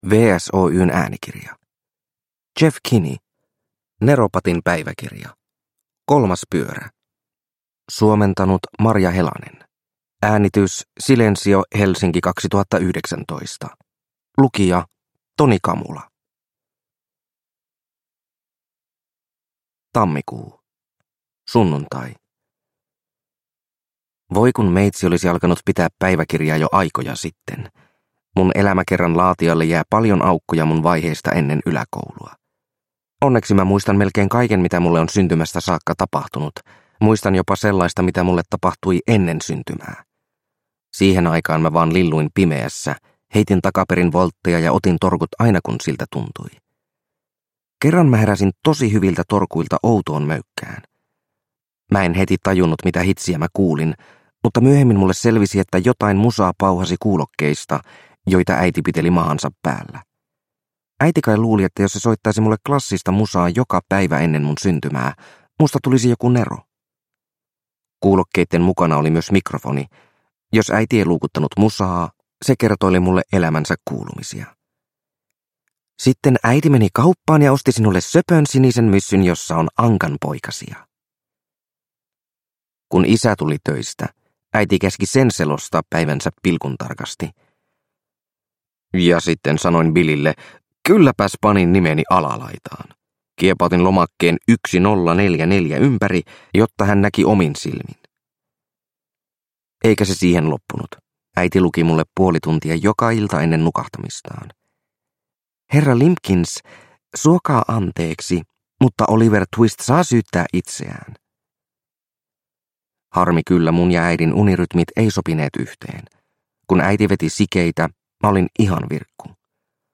Neropatin päiväkirja: Kolmas pyörä – Ljudbok – Laddas ner